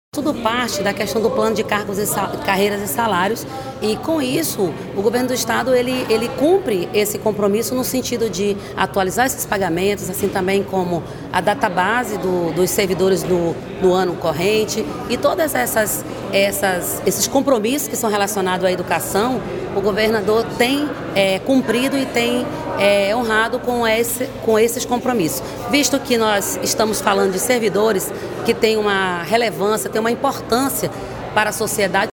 Segundo o governador Wilson Lima (União Brasil), os novos valores serão aplicados já na folha de pagamento de março, com retroativo referente a fevereiro:
PROGRESSAO-0703-B-WILSON-LIMA.mp3